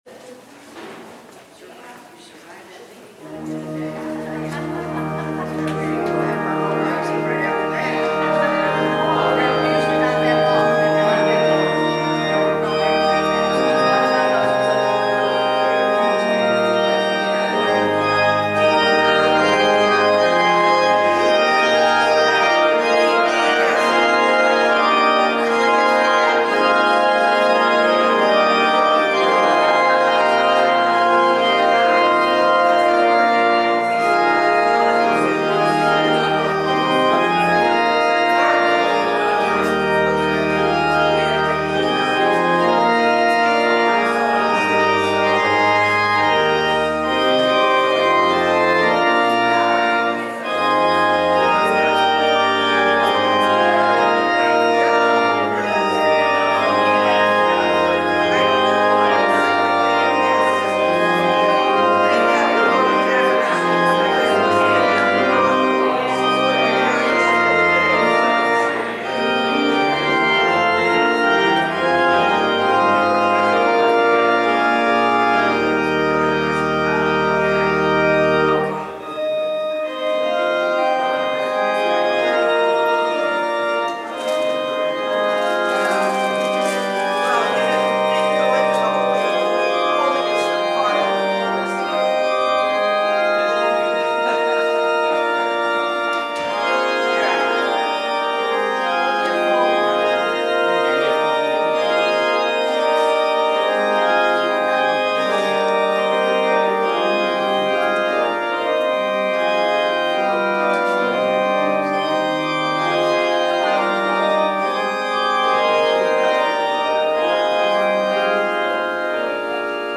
If the prelude showed the lyric quality of our organ, the postlude showed its grandeur.
Remember,  these are clips of live performance, people coming and leaving the service. Regular service music. That’s why the chatter in the background.
postlude-8-30.mp3